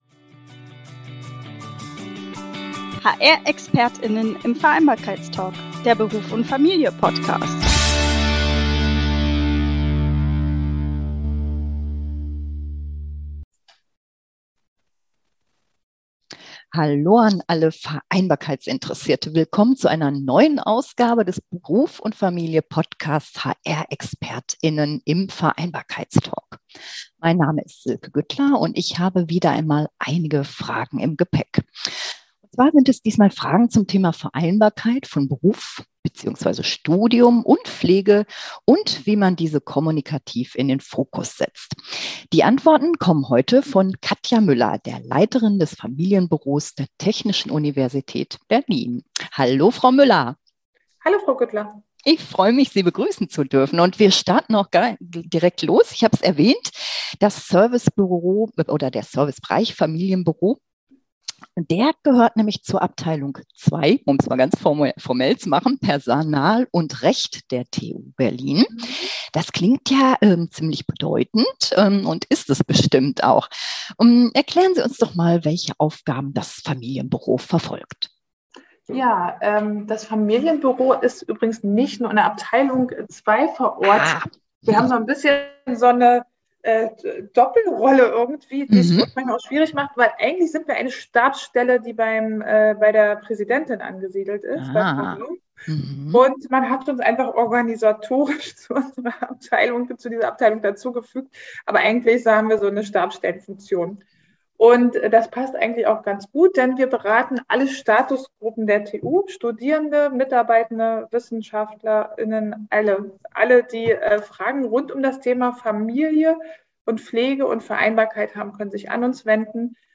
TU Berlin ~ HR-Expert*innen im Vereinbarkeits-Talk Podcast